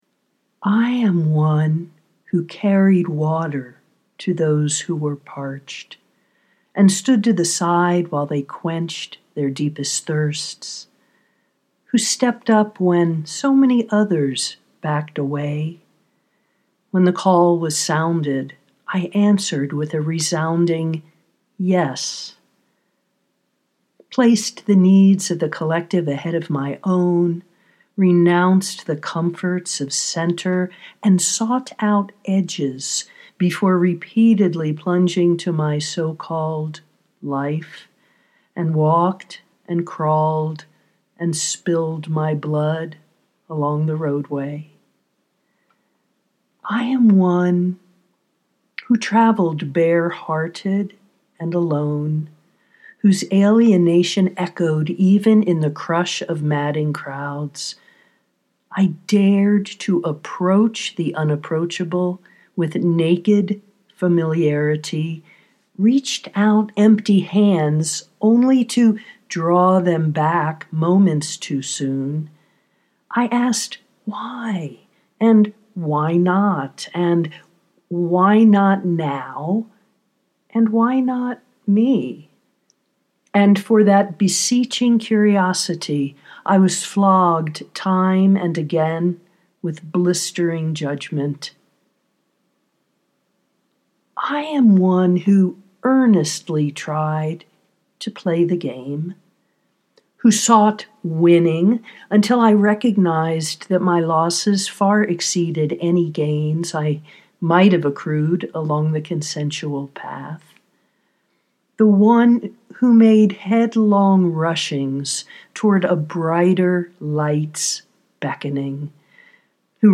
i am one, too (audio poetry 4:11)